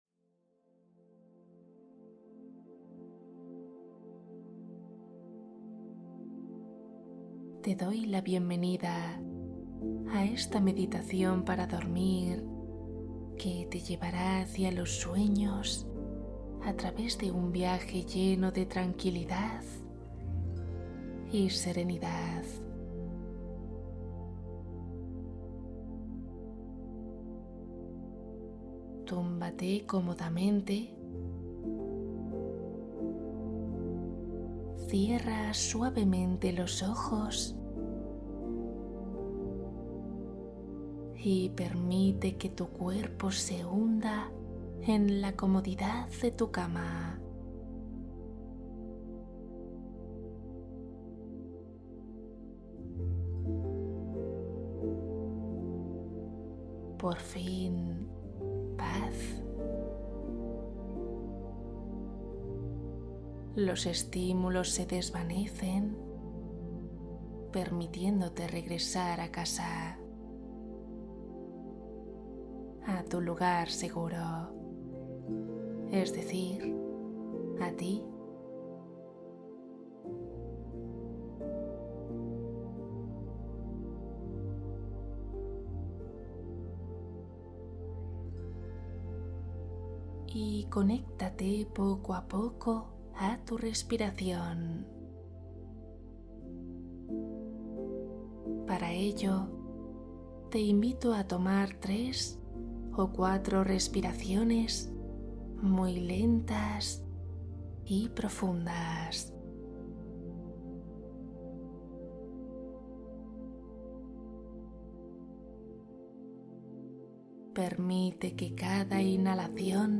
Duerme ✨ Meditación con cuento para vencer el insomnio